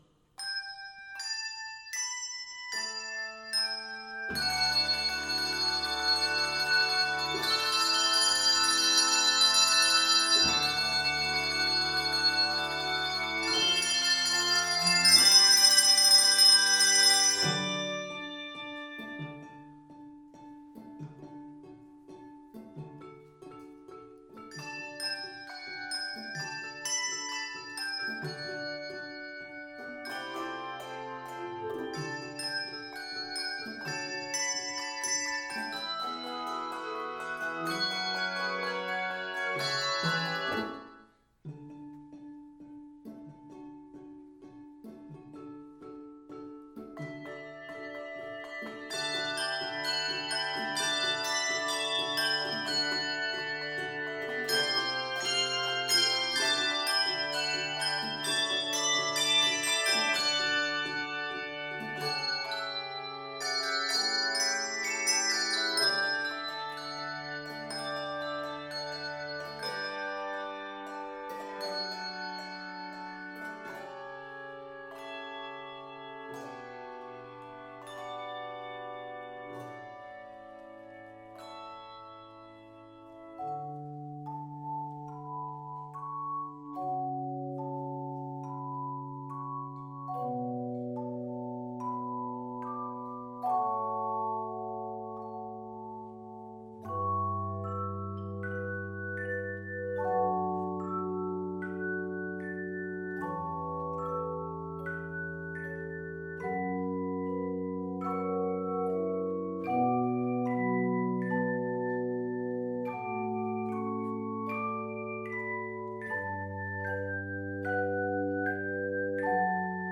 rollicking original tune
N/A Octaves: 3-7 Level